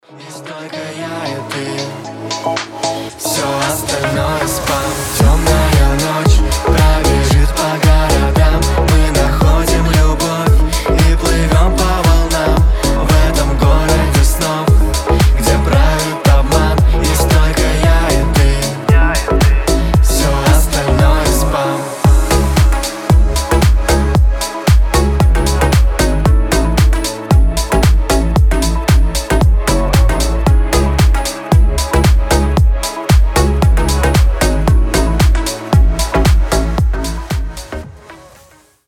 • Качество: 320, Stereo
deep house
женский голос